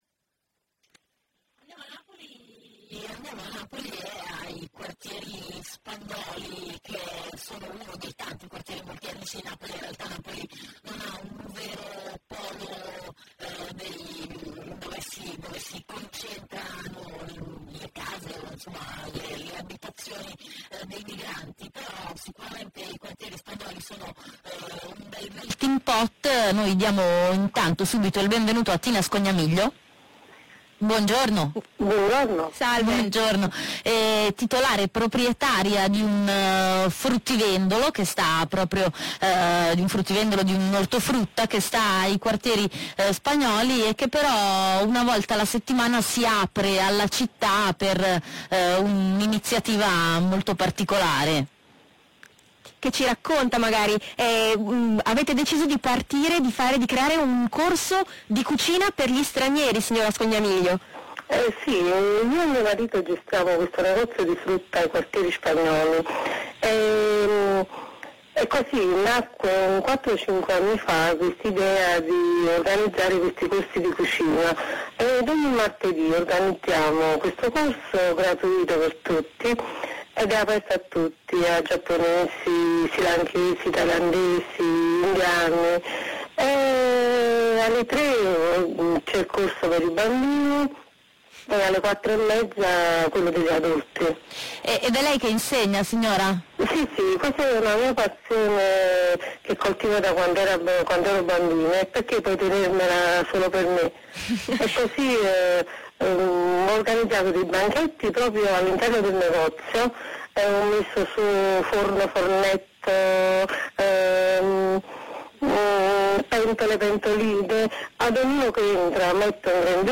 Intervista telefonica a Radio Popolare